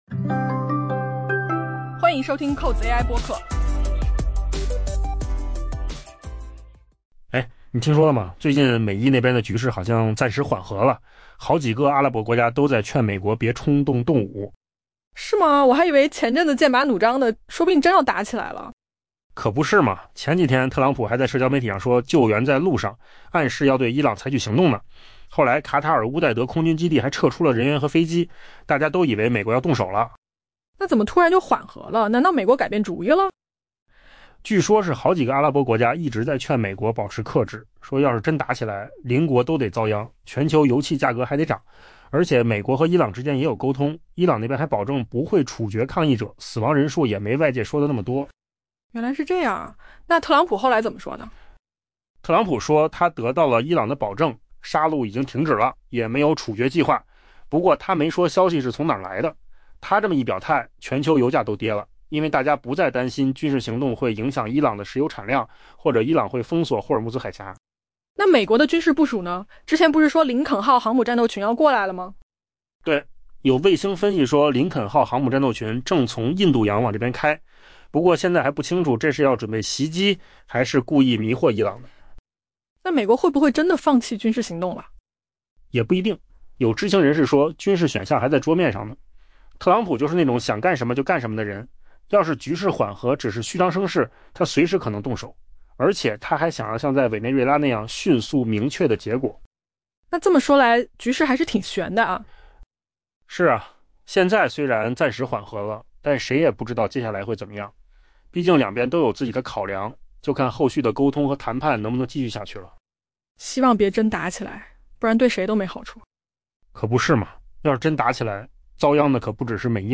AI 播客：换个方式听新闻 下载 mp3 音频由扣子空间生成 据三位接近阿拉伯政府的人士透露， 包括伊朗与美国政府之间的沟通在内，旨在劝阻美国总统特朗普对伊朗动武的密集外交努力，已使海湾地区的紧张局势降温。